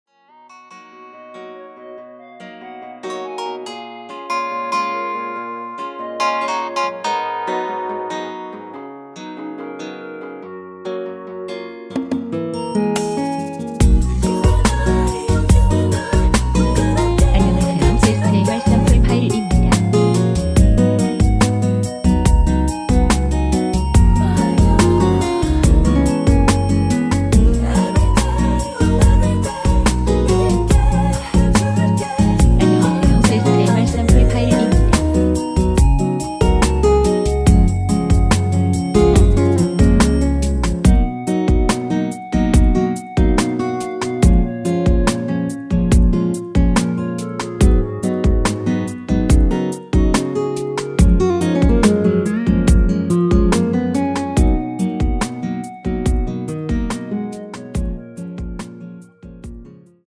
미리듣기에서 나오는 부분이 이곡의 코러스 전부 입니다.(원곡에 코러스가 다른 부분은 없습니다.)
원키에서(-3)내린 멜로디와 코러스 포함된 MR입니다.(미리듣기 확인)
F#
앞부분30초, 뒷부분30초씩 편집해서 올려 드리고 있습니다.